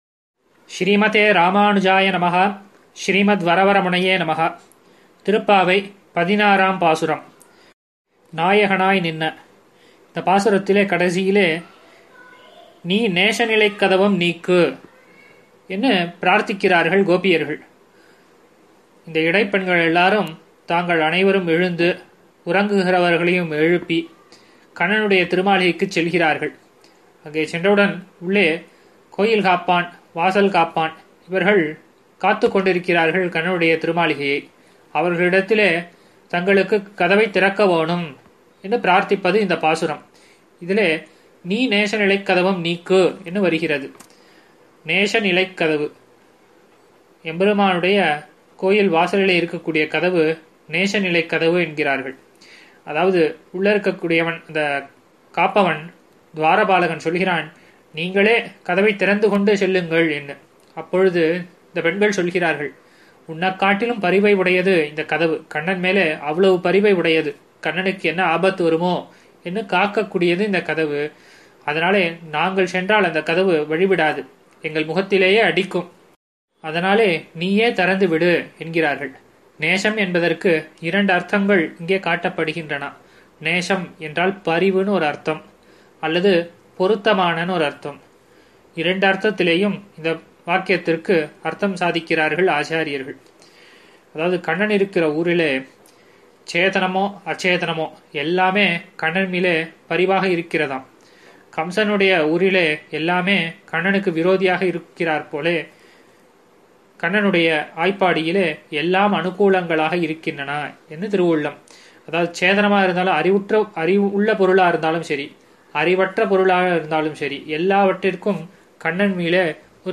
சார்வரி ௵ மார்கழி ௴ மஹோத்ஸவ உபன்யாசம் –